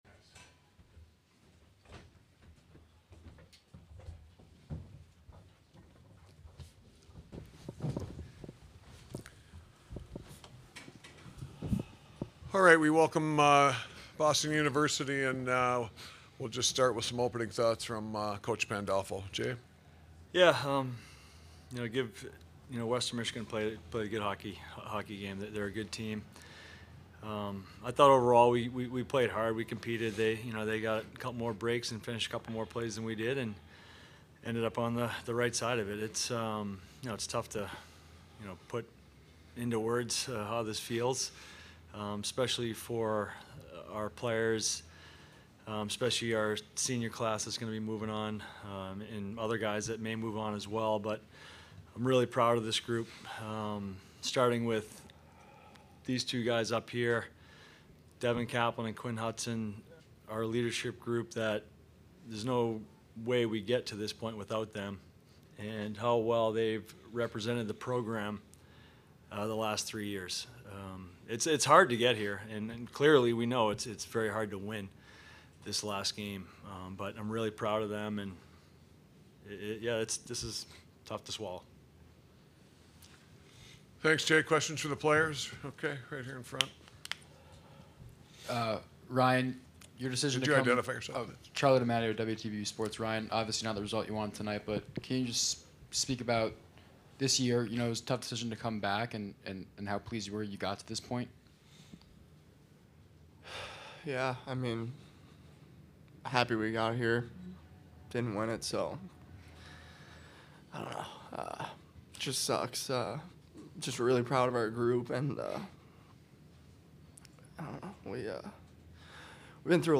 Western Michigan Postgame Media